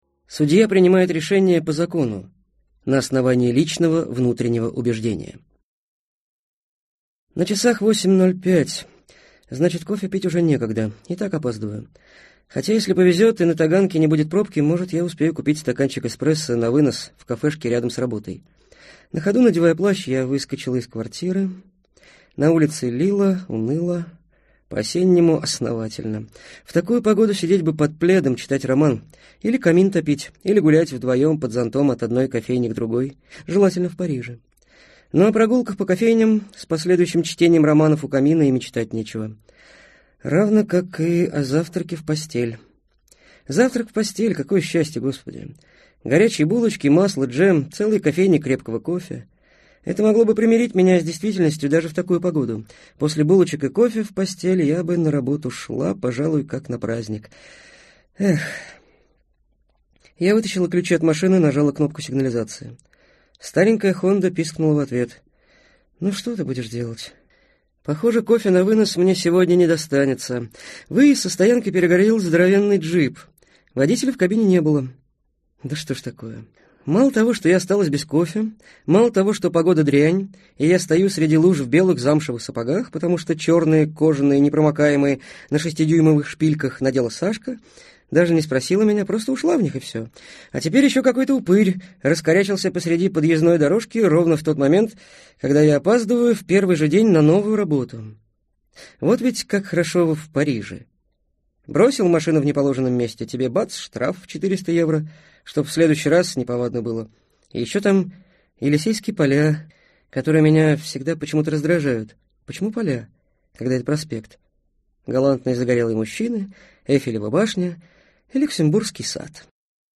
Аудиокнига Божий дар | Библиотека аудиокниг